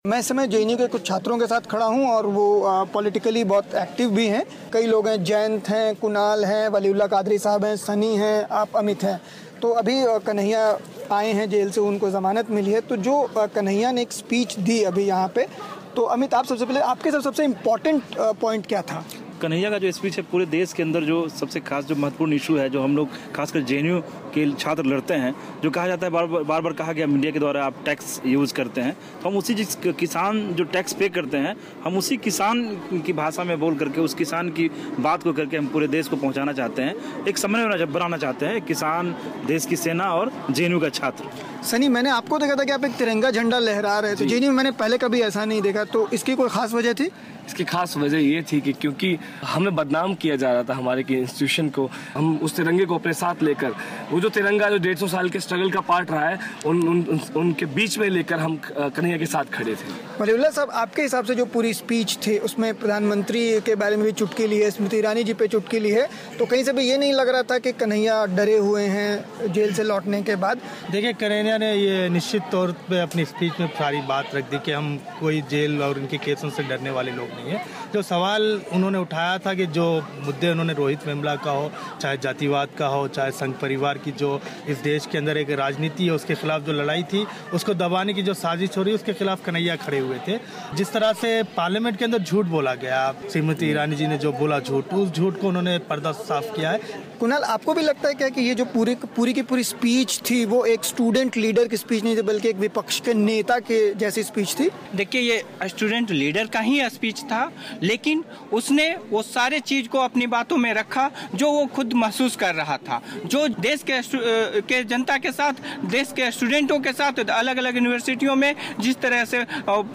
जेएनयू में कई छात्रों से बात की